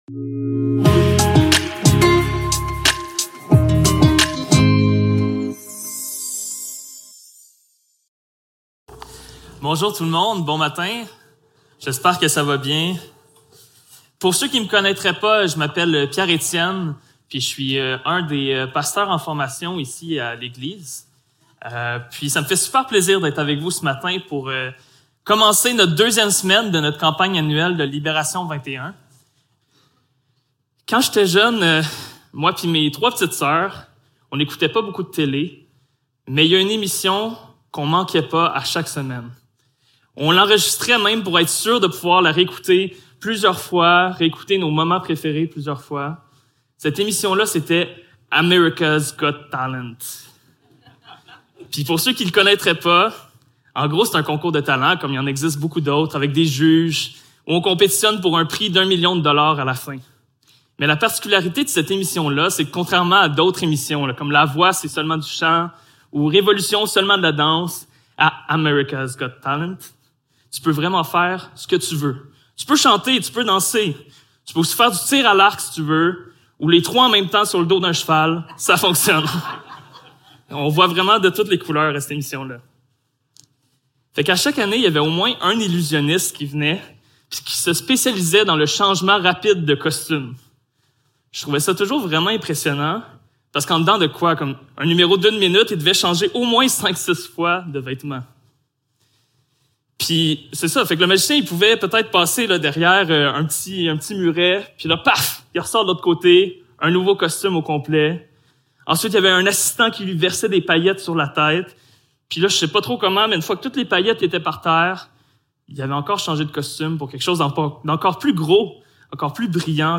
Éphésiens 4.17-25 Service Type: Célébration dimanche matin Description